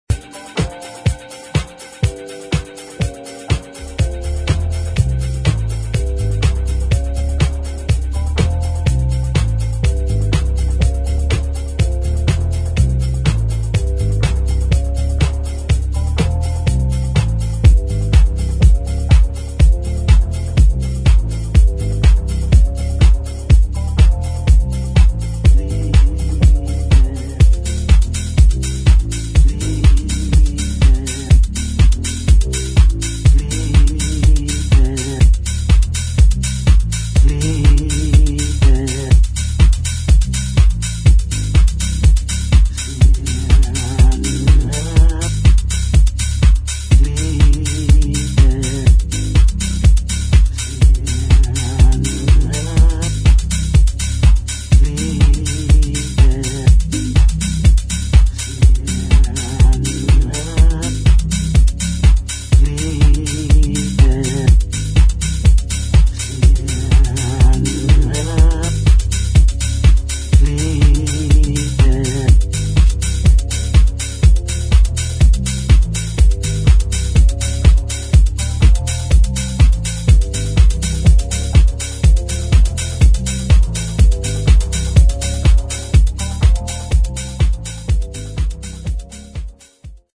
[ DEEP HOUSE / TECHNO ]